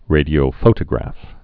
(rādē-ō-fōtə-grăf)